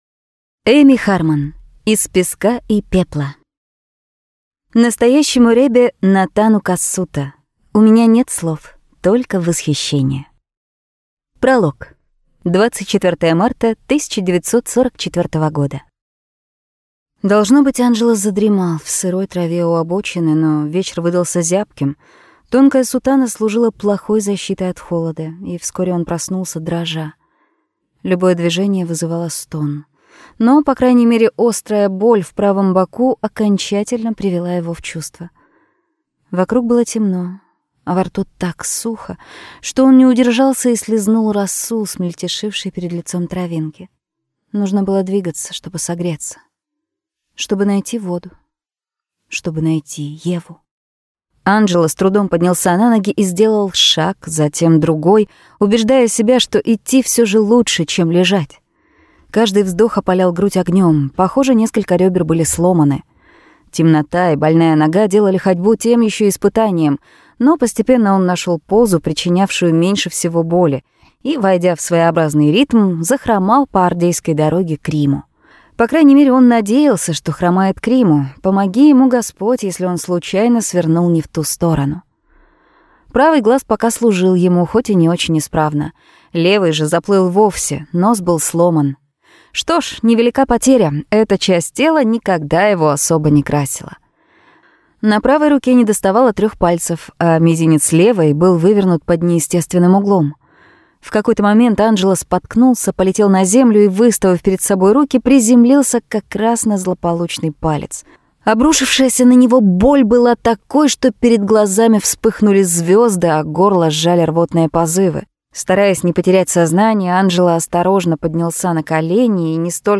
Аудиокнига Из песка и пепла | Библиотека аудиокниг